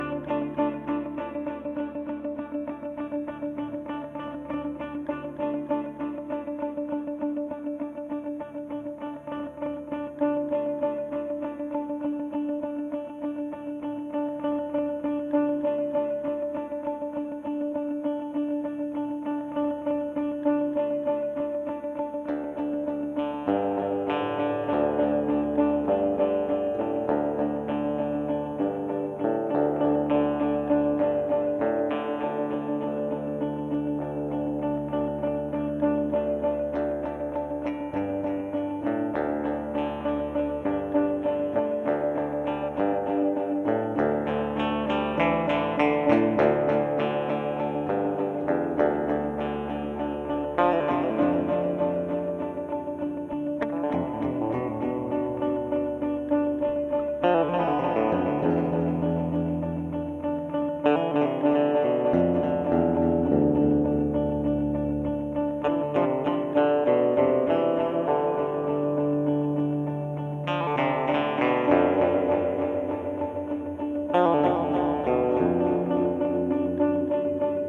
These are a single phrase split in two.